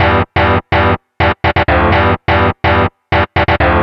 cch_synth_loop_maurice_125_C.wav